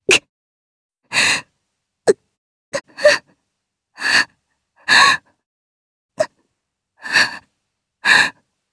Lewsia_B-Vox_Sad_jp.wav